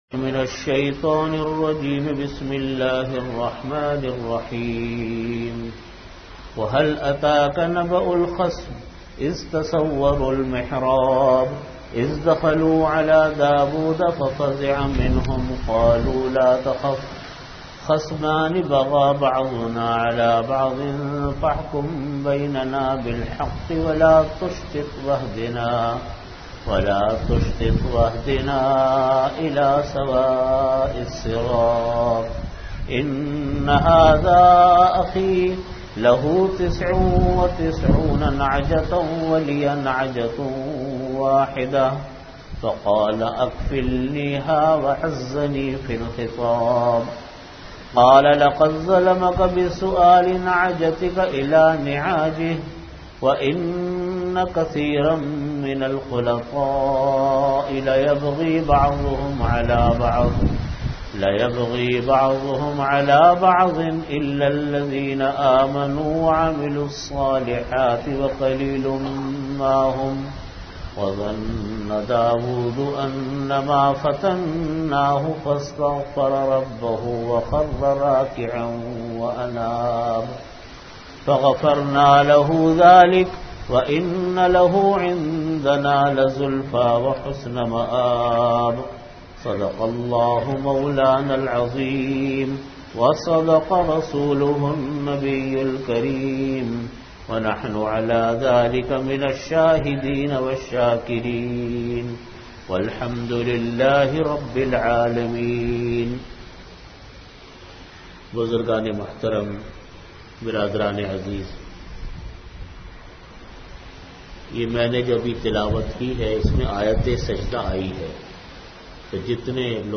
Category: Tafseer
Time: After Asar Prayer Venue: Jamia Masjid Bait-ul-Mukkaram, Karachi